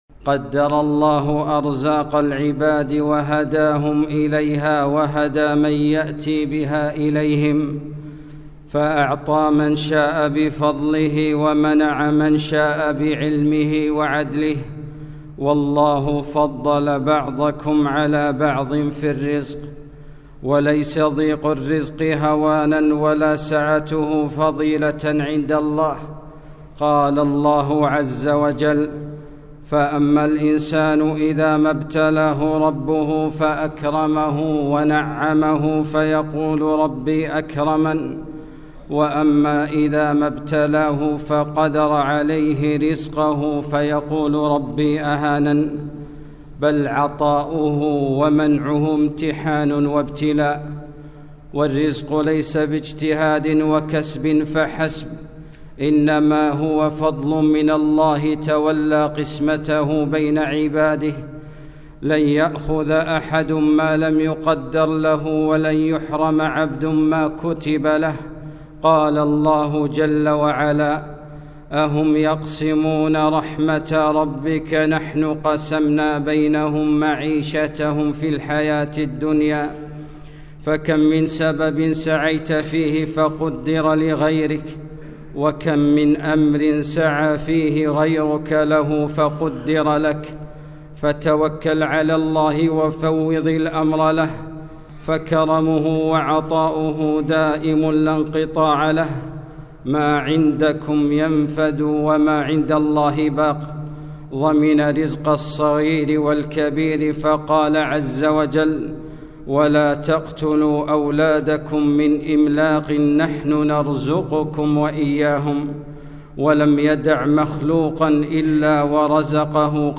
خطبة - ( وفي السماء رزقكم )